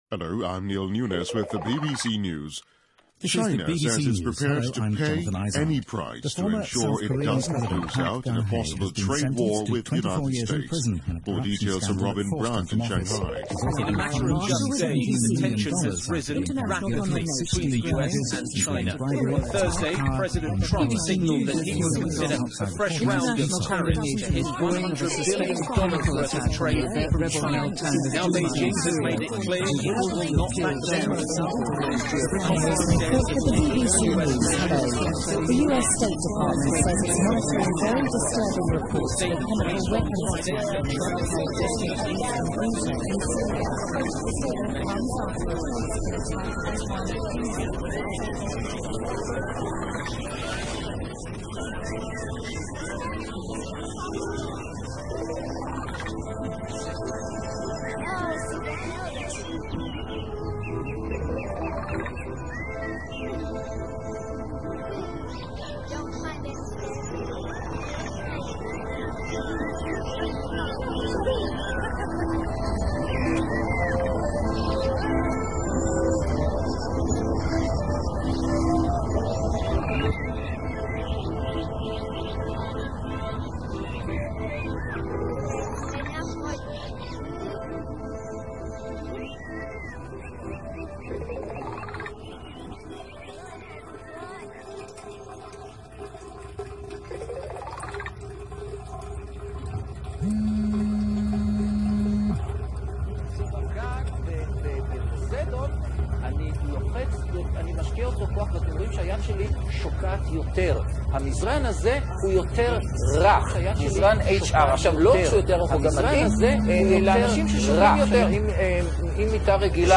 Radio Concrete is a monthly experimental radio show focusing on sounds we’re exposed to every day in the public and domestic spheres - using field recordings, amplified and toys and live sources such as am/fm radio.